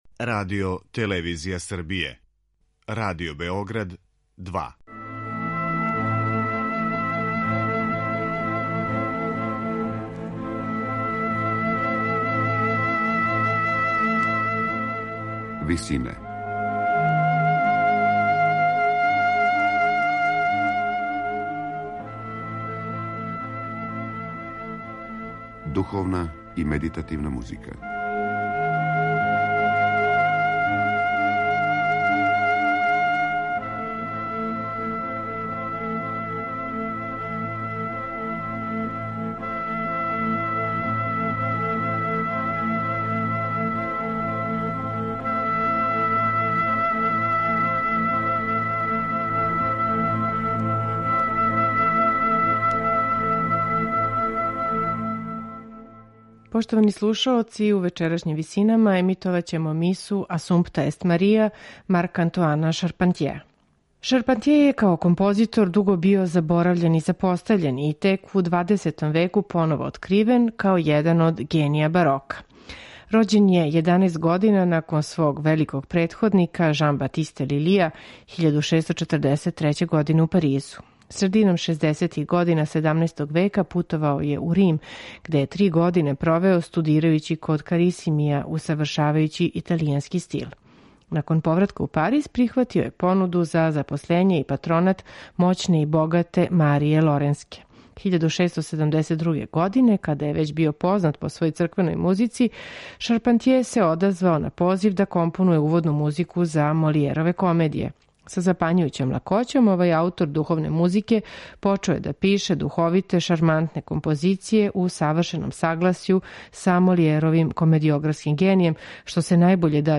Миса
Слушаћете је у интерпретацији ансамбла Les Arts Florissants / Цветајуће уметности, под управом Вилијама Кристија.